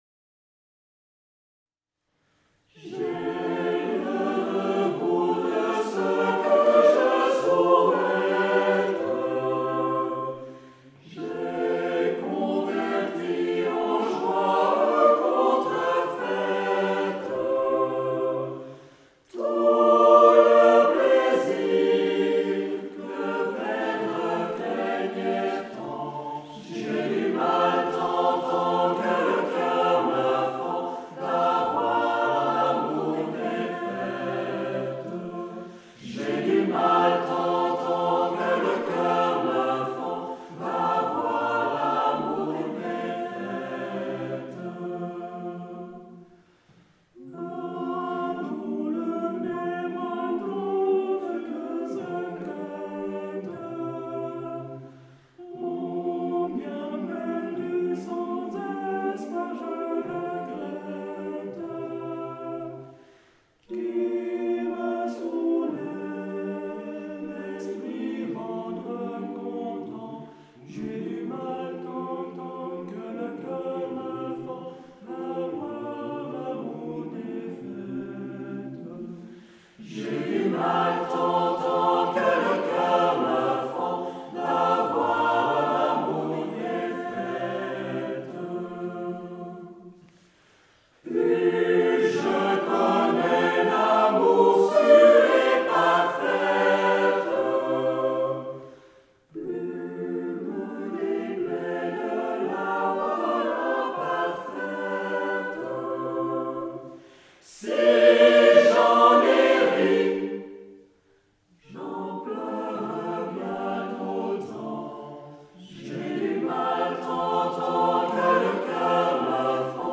Polyphonie profane et sacrée d'Europe et des Amériques du XIVe au XXIe sièclephoto 2011
- Le vendredi 4 novembre 2011 à 20h00 à l'église de Montricher, VD, Suisse.
Quelques extraits de Montricher: